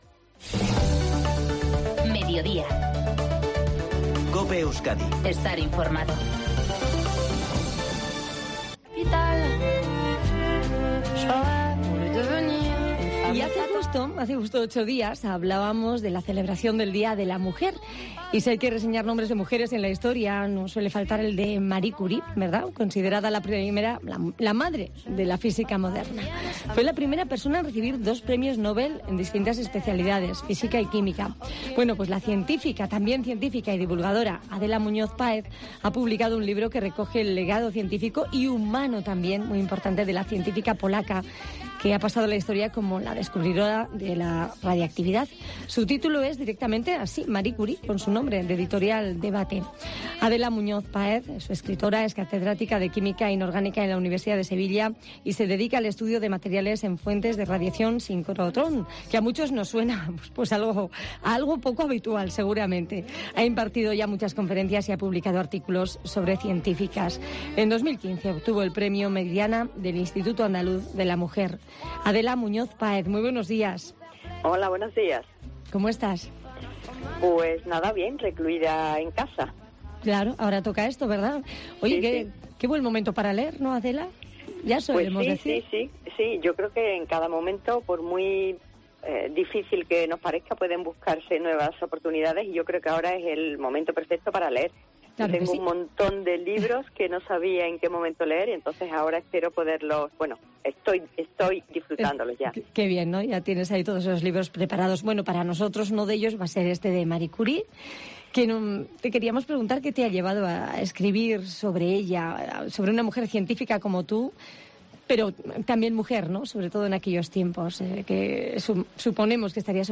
En la entrevista para COPE Euskadi